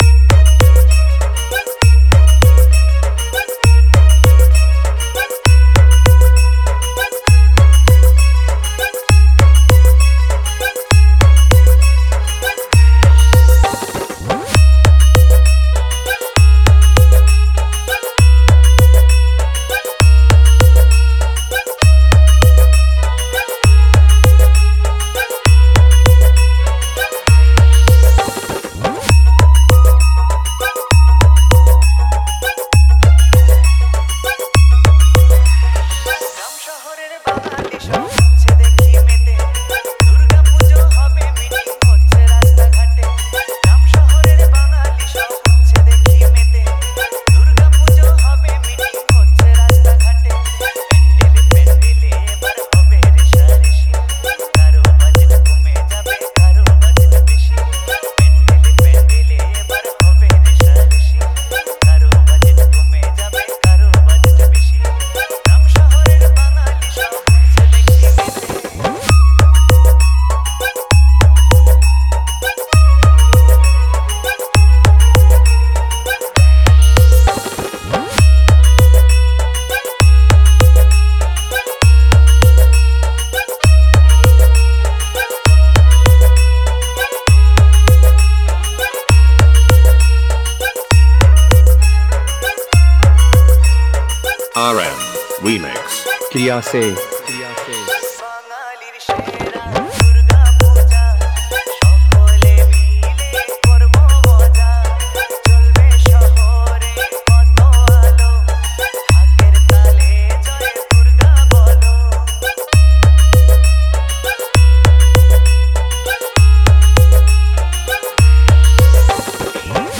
দুর্গ উৎসব স্পেশাল বাংলা নতুন স্টাইল ভক্তি হামবিং মিক্স 2024